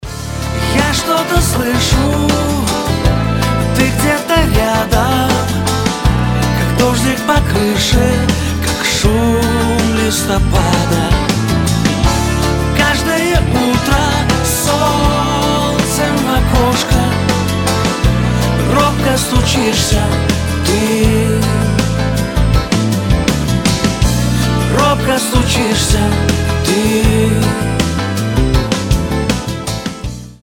• Качество: 320, Stereo
поп
романтичные
эстрадные